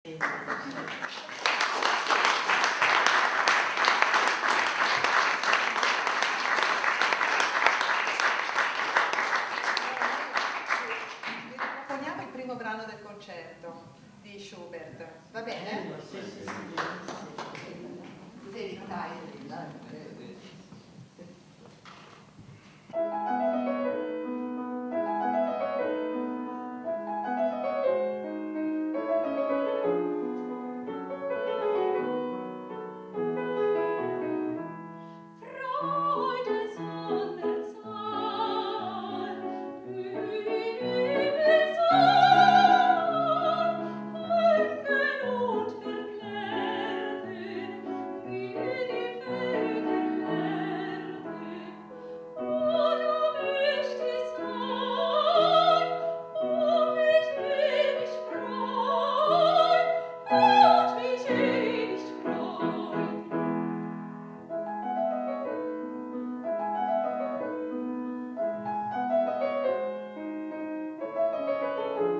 Concerto "Sulle ali del canto"